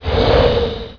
pend_swing.wav